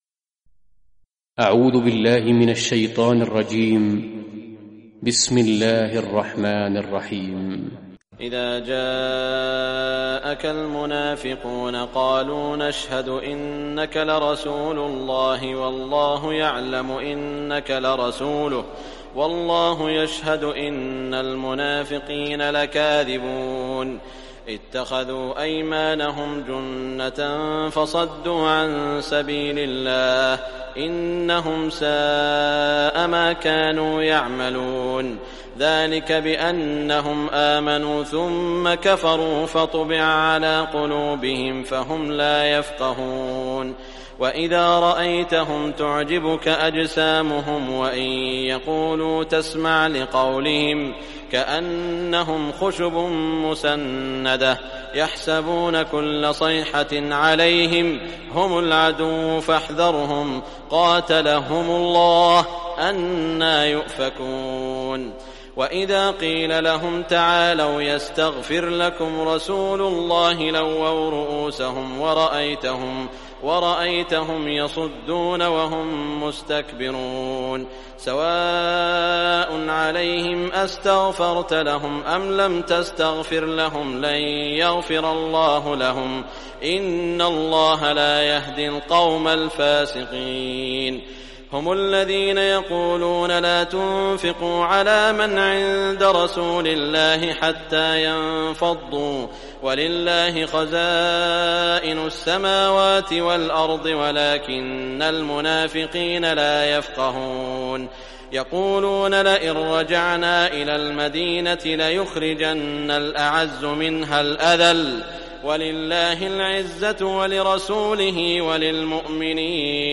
Surah Munafiqun Recitation by Sheikh Shuraim
Surah Munafiqun, listen or play online mp3 tilawat / recitation in Arabic in the beautiful voice of Sheikh Saud al Shuraim.